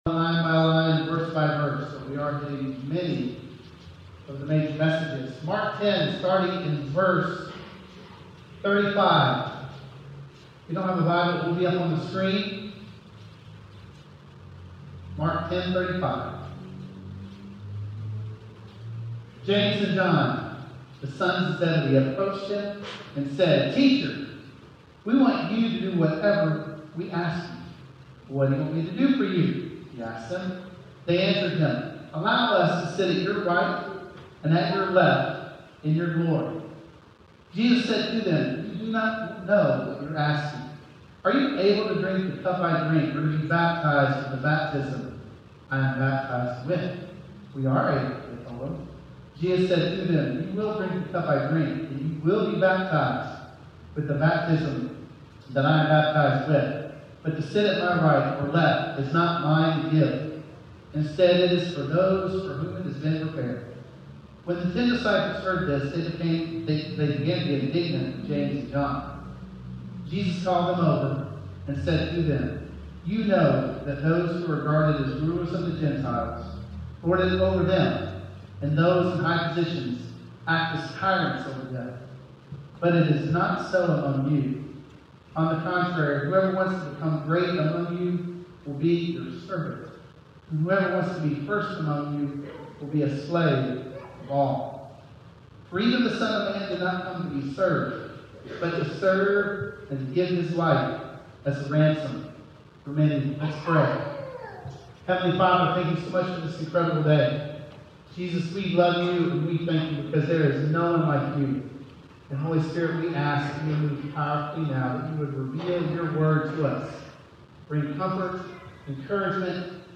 Suffering Servant - Sermon - Woodbine